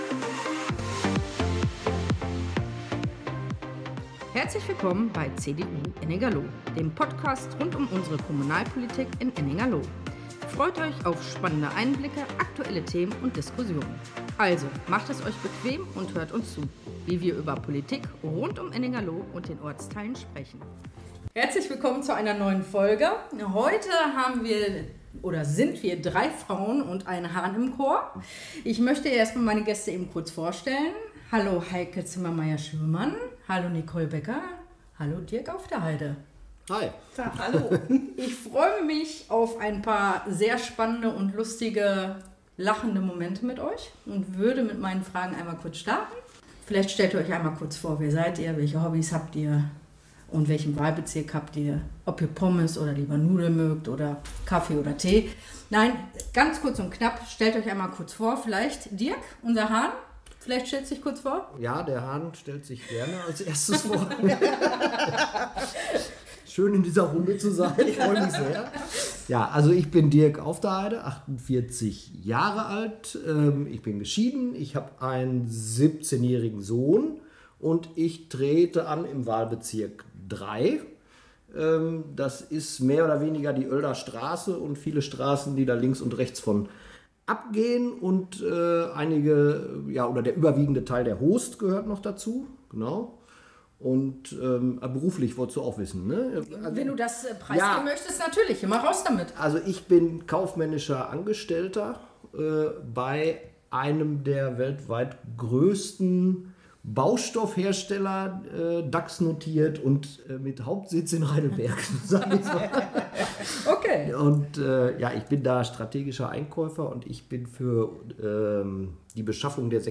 Lively-Instrumental Intro und Outro